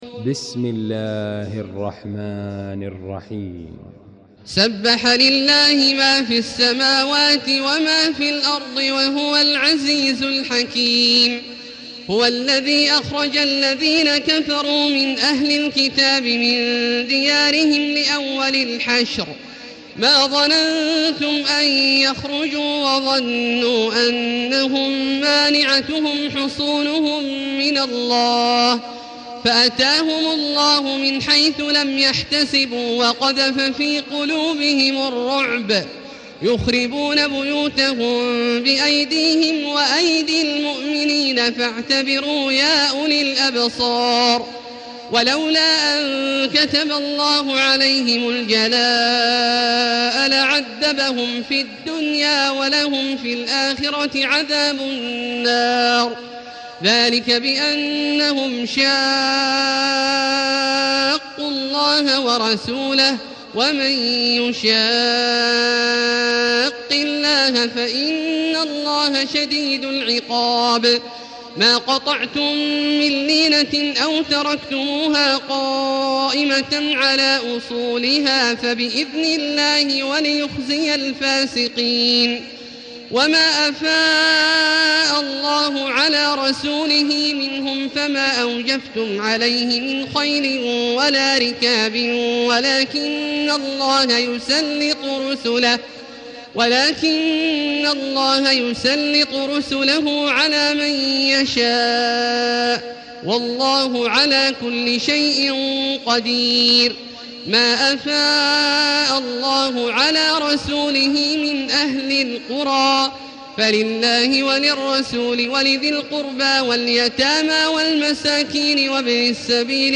المكان: المسجد الحرام الشيخ: فضيلة الشيخ عبدالله الجهني فضيلة الشيخ عبدالله الجهني الحشر